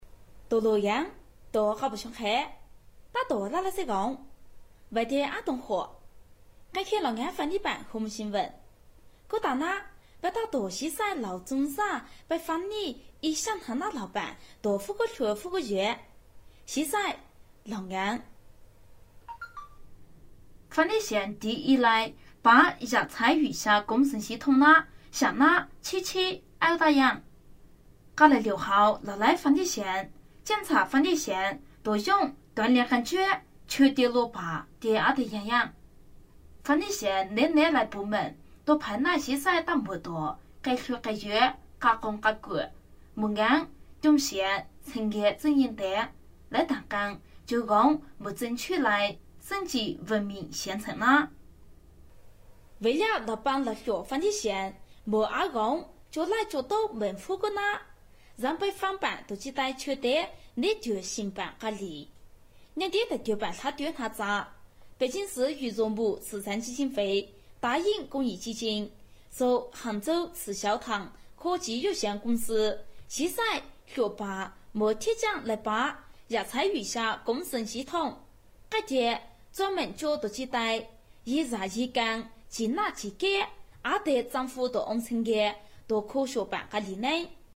方言青年沉稳 、娓娓道来 、积极向上 、亲切甜美 、女宣传片 、课件PPT 、工程介绍 、绘本故事 、动漫动画游戏影视 、颁奖主持 、看稿报价女苗语01 贵州黔东南 新闻主播 讲述风格 沉稳|娓娓道来|积极向上|亲切甜美
女苗语01 贵州黔东南 播音主持讲故事广告旁白叙述讲解 沉稳|娓娓道来|积极向上|亲切甜美